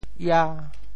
潮语发音
ia5